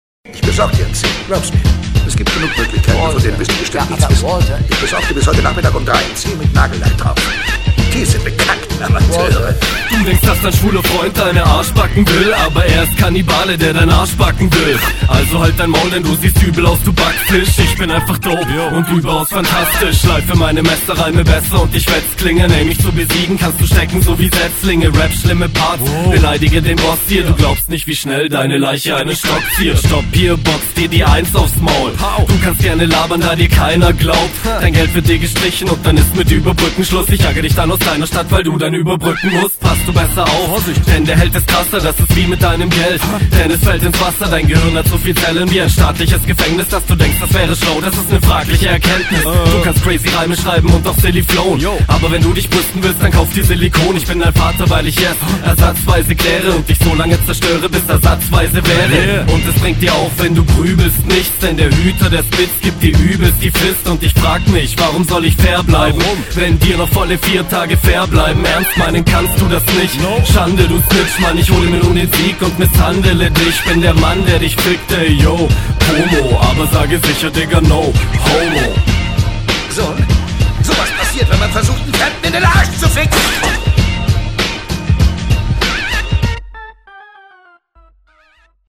Das ist einfach fast nochmal HR1, Flow wieder ähnlich und der Text wieder komplett ohne …